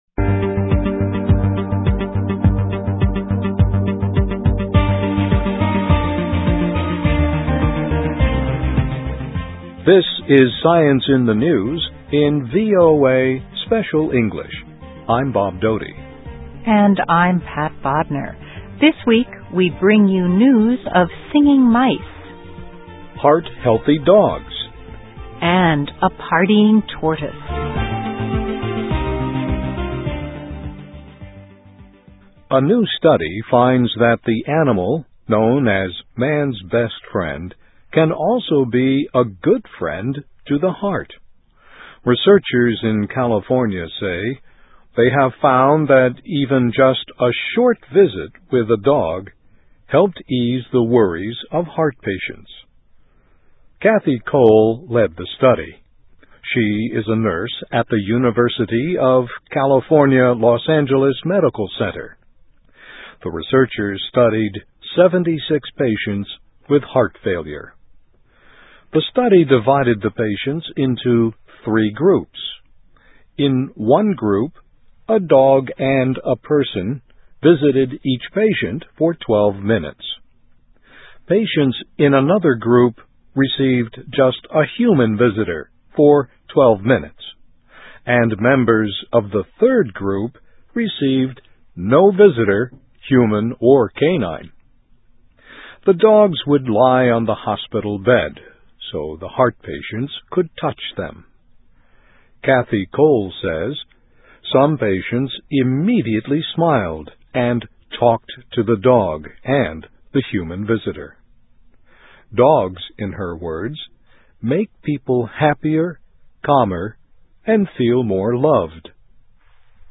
Dogs May Be Just What the Doctor Ordered for Worried Heart Patients (VOA Special English 2005-12-05)
se-science-in-the-news-6dec05.mp3